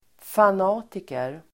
Uttal: [fan'a:tiker]